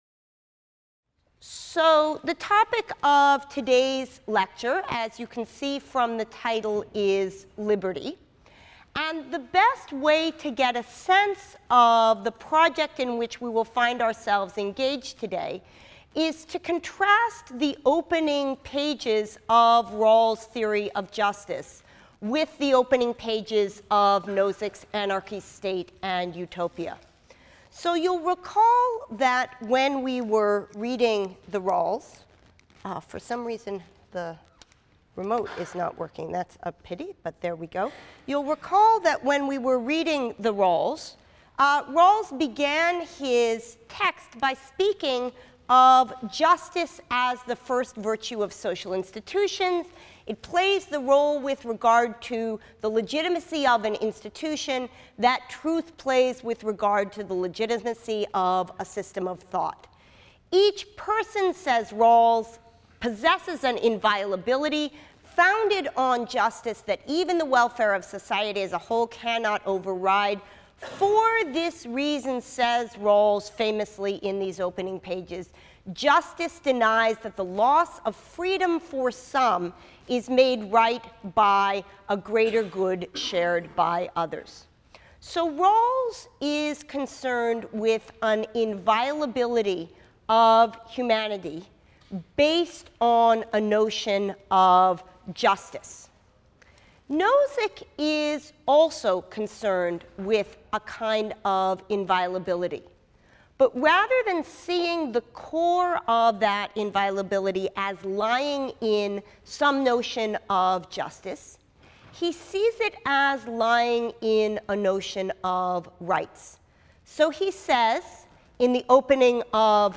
PHIL 181 - Lecture 22 - Equality II | Open Yale Courses